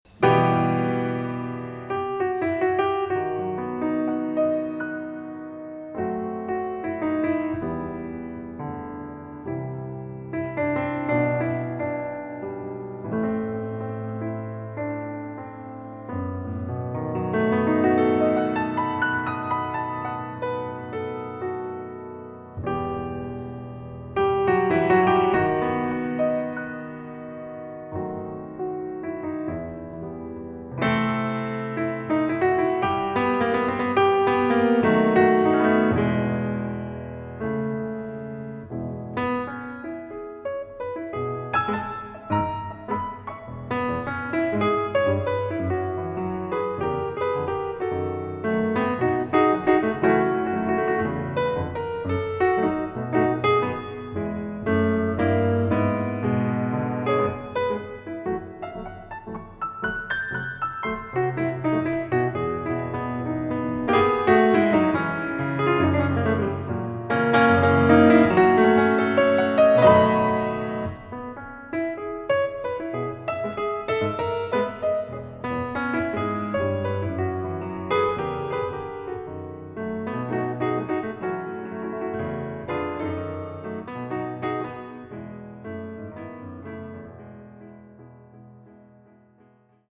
Jazz, Swing og Evergreens på piano